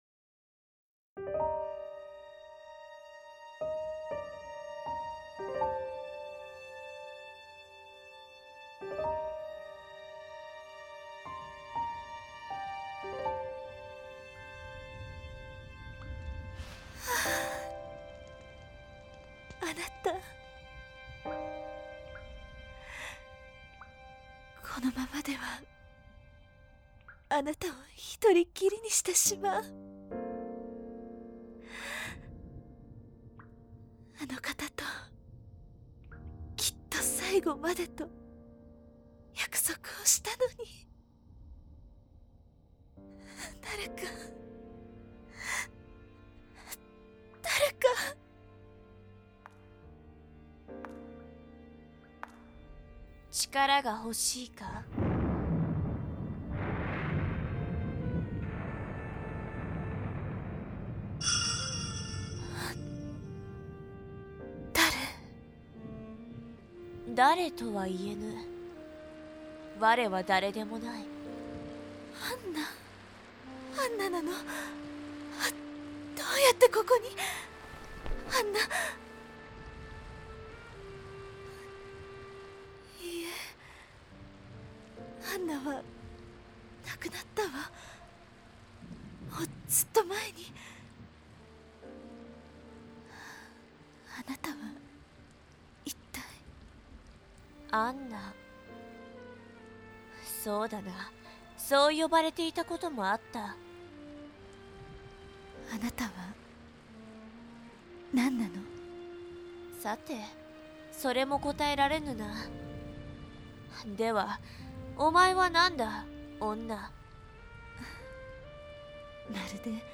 Voice Drama